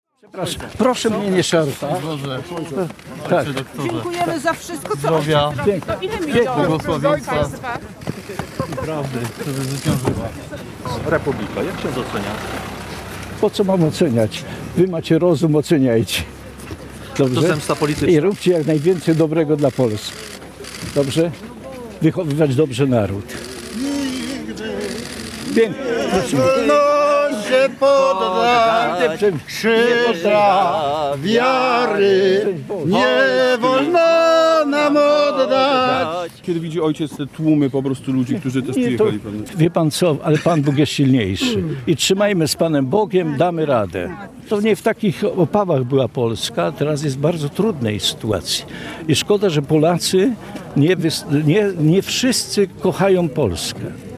Kilka minut przed godziną 13:00 na miejsce dotarł ojciec Tadeusz Rydzyk. Skierował do zgromadzonych mediów oraz swoich słuchaczy krótkie przemówienie: